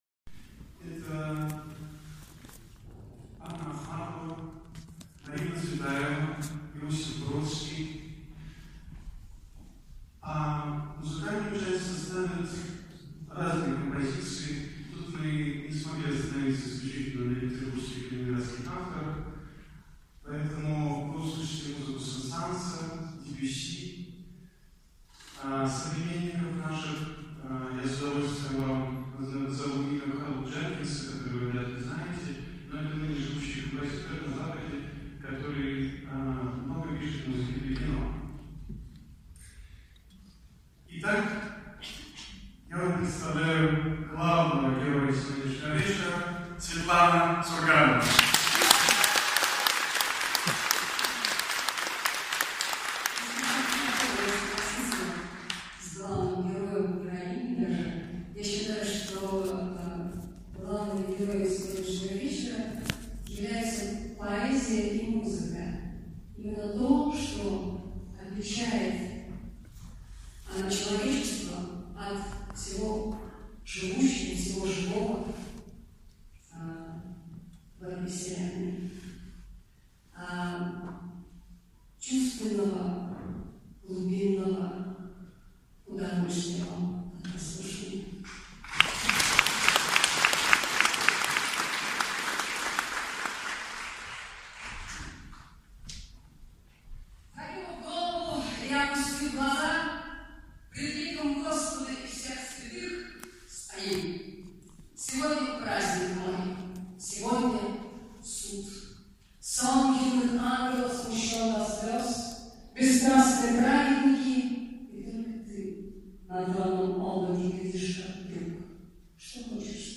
svetlana-surganova-nachalo-poeticheskogo-vechera-marina-tsvetaeva-zakinuv-golovu-i-opustiv-glaza-2